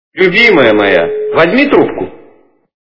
» Звуки » Люди фразы » Любимая моя - возьми трубку
При прослушивании Любимая моя - возьми трубку качество понижено и присутствуют гудки.